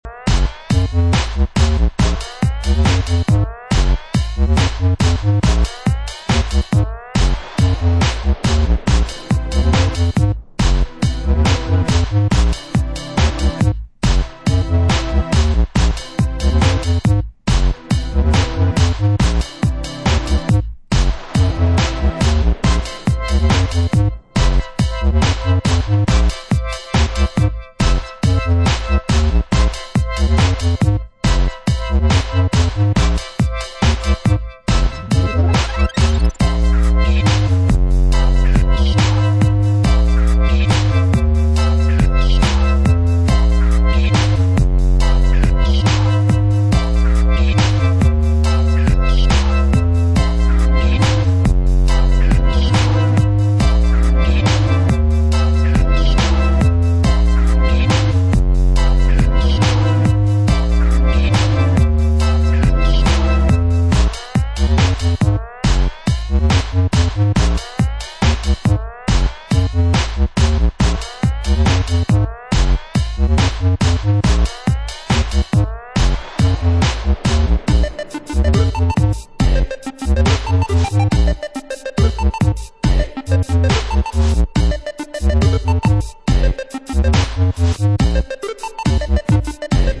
wonky/bleep dubstep